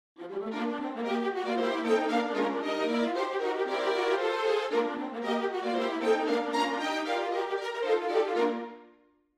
veloce realizzazione di 8 battute della “Overture Miniature” dallo Schiaccianoci di Peter Ilyitch Tchaikovsky (˜150 KB), non c’è riverbero né equalizzazione, utilizzando tracce separate come l’originale tranne per la linea di viola staccato a cui ho dedicato una patch tutta per lei con lo spiccato ad arcate alternate.